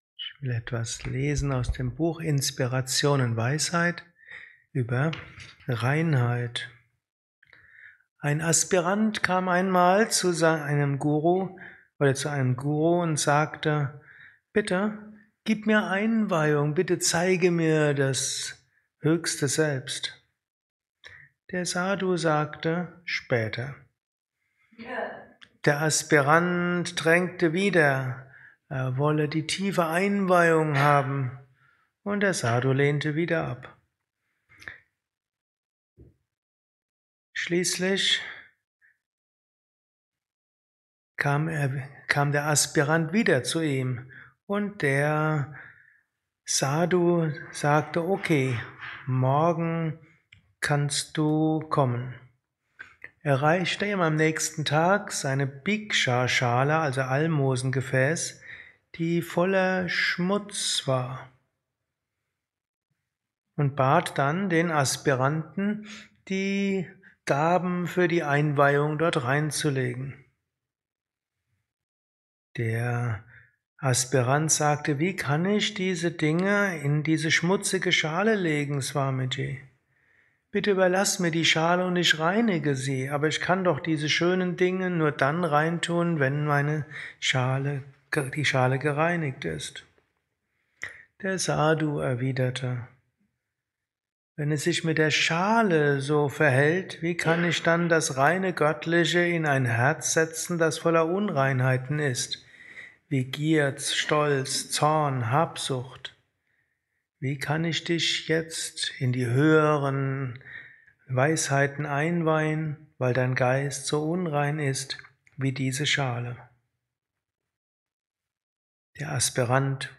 Vortrag als Inspiration für den heutigen Tag
Satsangs gehalten nach einer Meditation im Yoga Vidya Ashram Bad